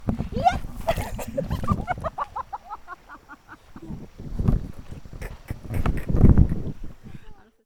웃음.ogg